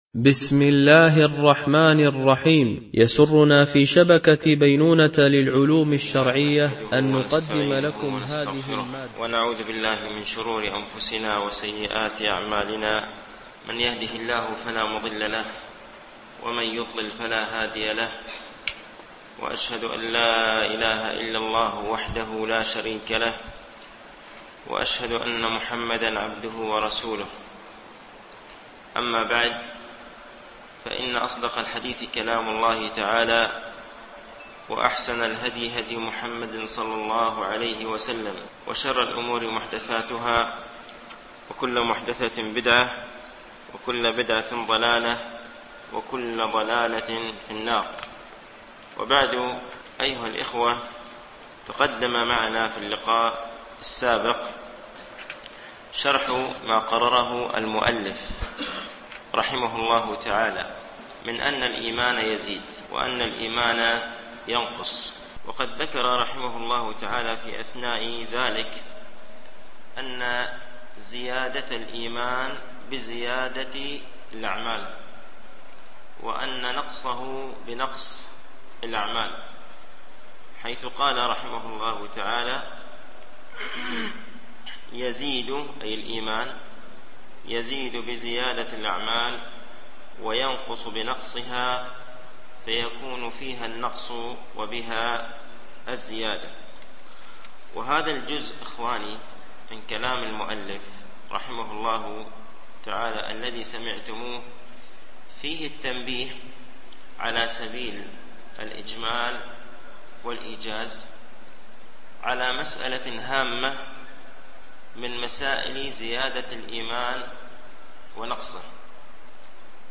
شرح مقدمة ابن أبي زيد القيرواني ـ الدرس الستون